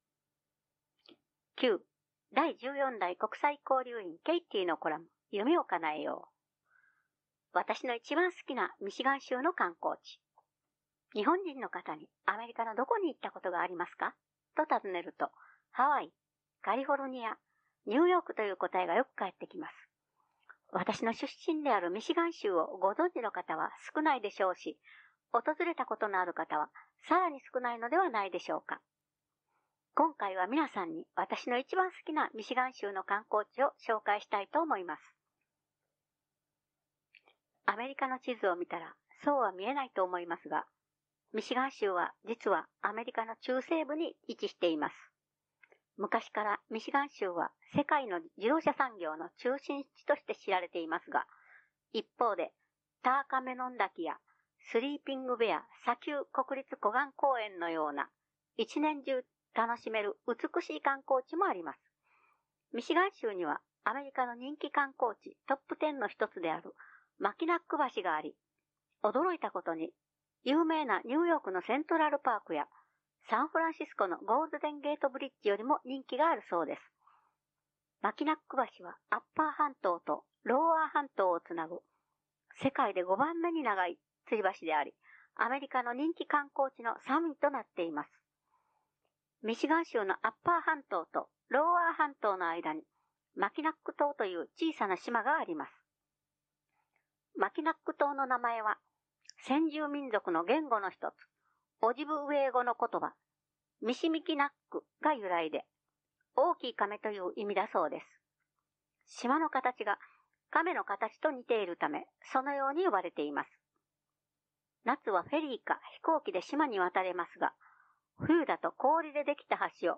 広報誌「華創」8月号を、朗読ボランティア「ひびき」の皆さんが朗読した音声を聞くことができます|精華町